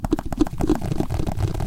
Мультяшный звук бегущей гусеницы